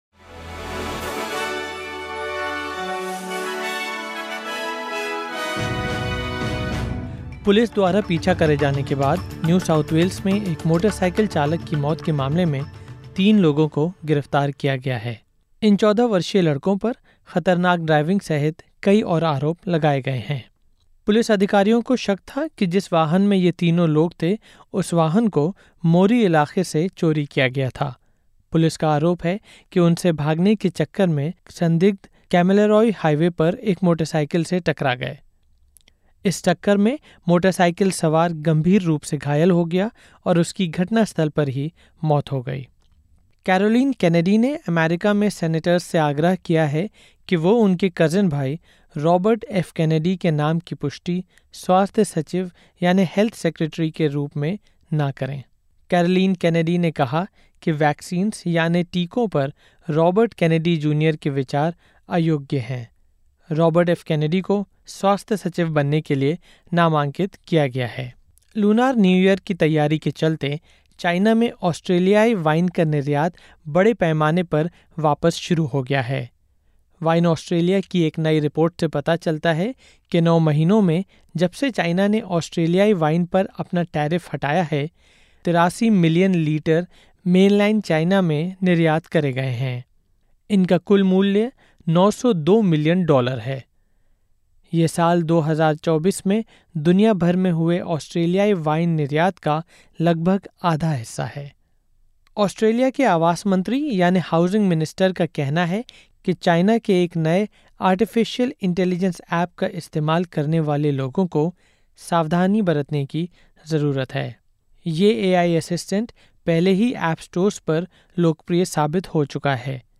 सुनें ऑस्ट्रेलिया और भारत से 29/01/2025 की प्रमुख खबरें।